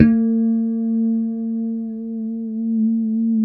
B3 PICKHRM1B.wav